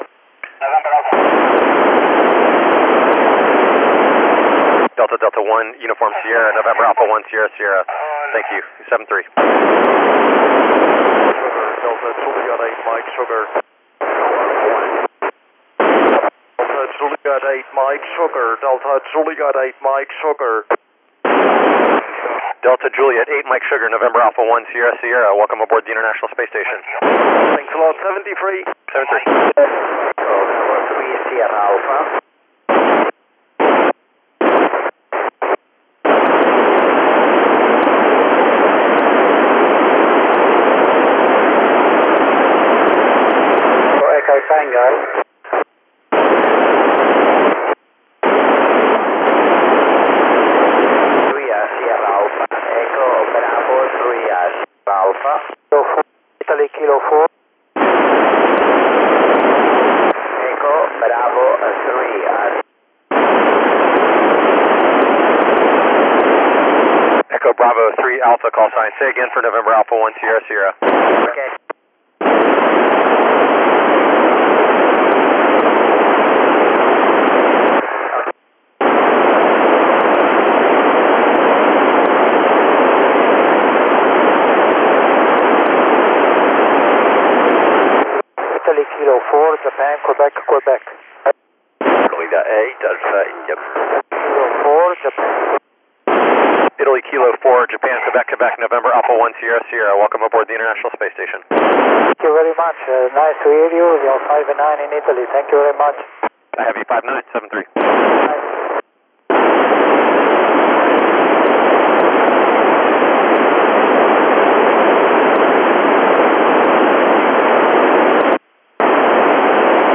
He was using the repeater in the ISS and the callsign NA1SS.